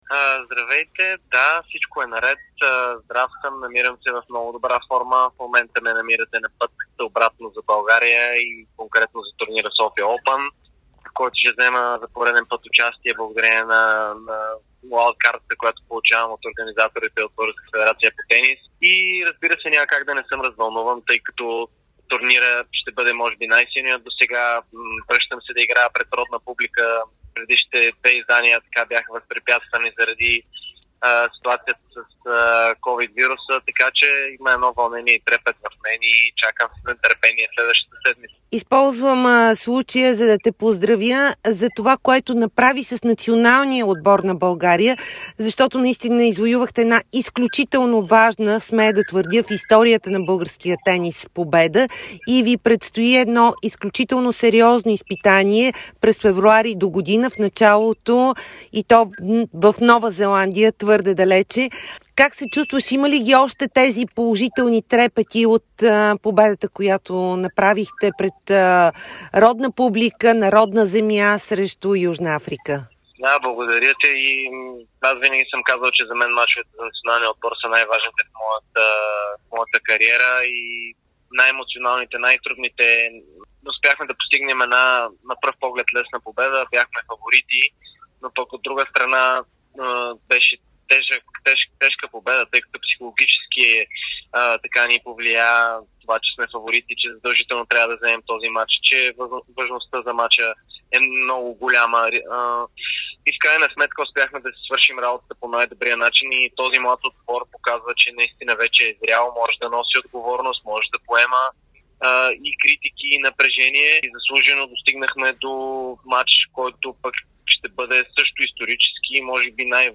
Втората тенис ракета на България Димитър Кузманов очаква пълни трибуни в Арена Армеец за ATP турнира у нас София Оупън от 25ти септември до 2ри октомври. На път за България снощи той даде интервю за Дарик радио и dsport, в което заяви, че успехът на националния ни отбор за Купа Дейвис е благодарение на здравия колектив.